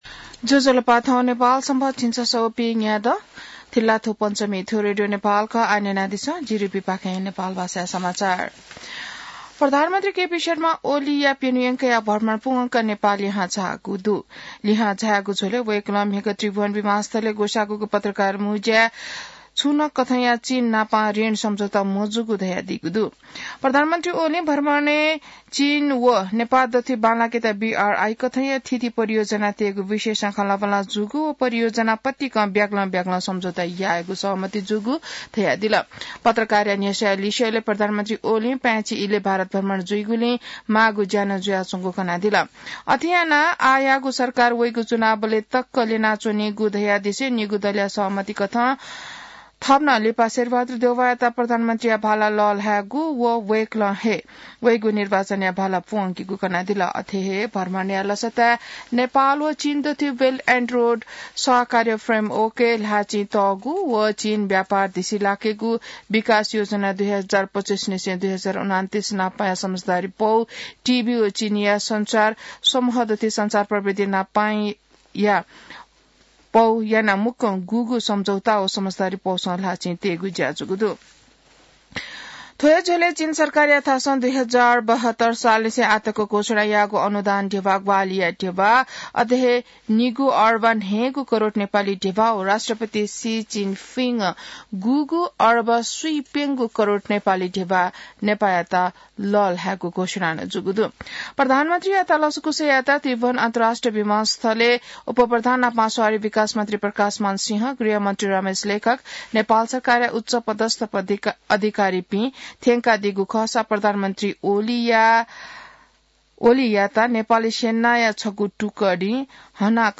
नेपाल भाषामा समाचार : २२ मंसिर , २०८१